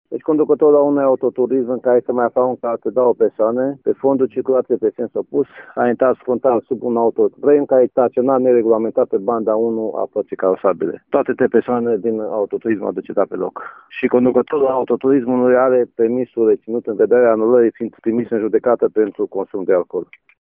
Șeful Poliției Rutiere din cadrul IPJ Mureș, Lucian Archiudean: